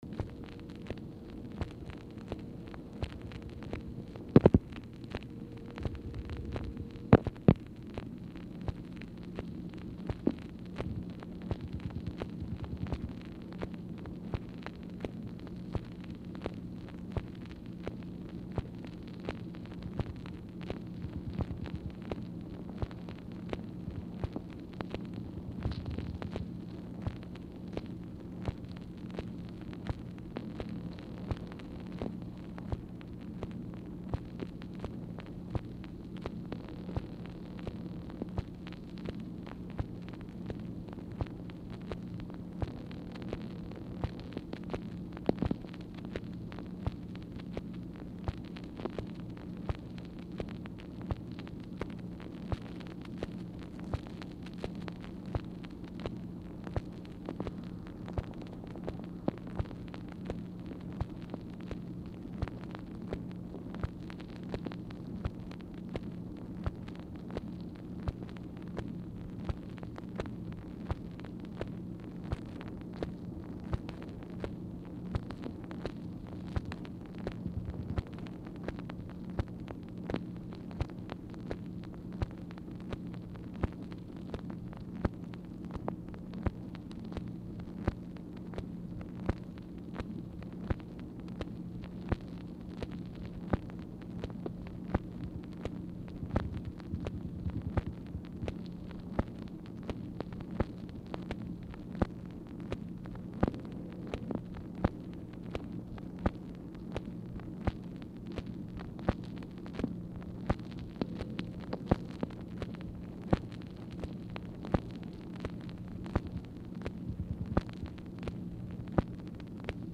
Telephone conversation # 2333, sound recording, MACHINE NOISE, 3/3/1964, time unknown | Discover LBJ
Format Dictation belt
Specific Item Type Telephone conversation